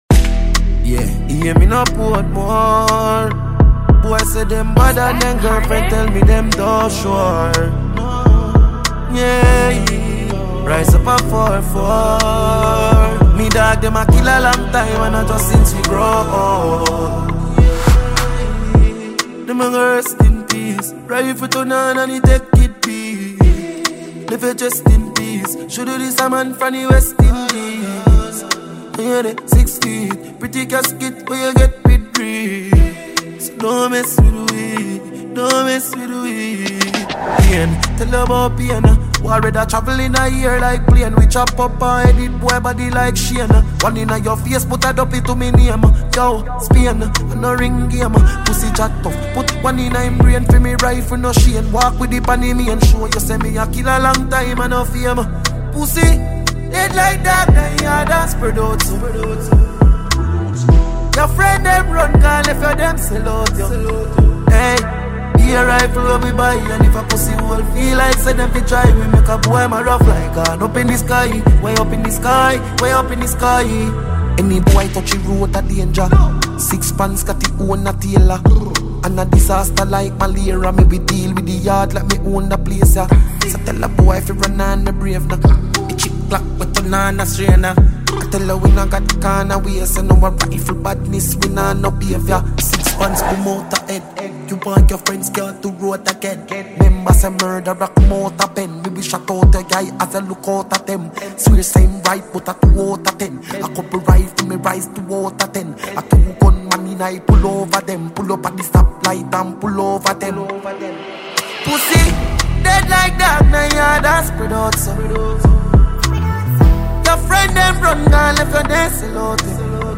Dancehall